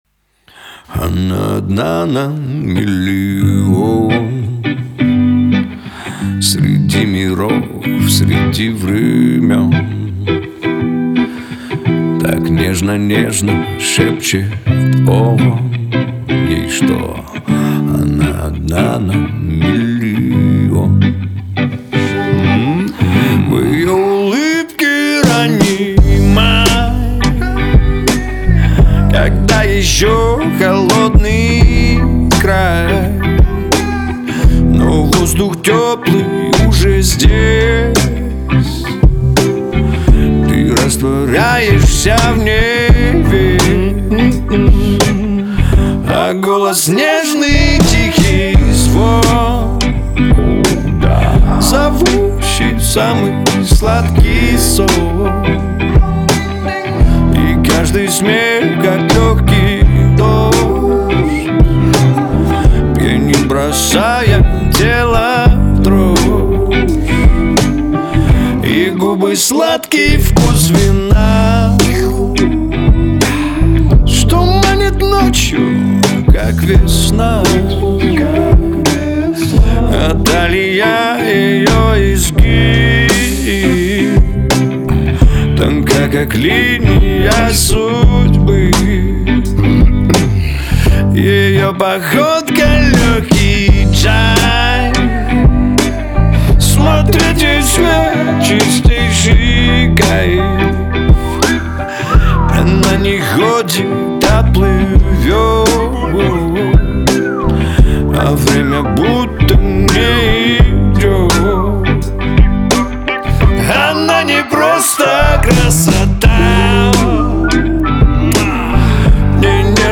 Качество: 320 kbps, stereo
Поп музыка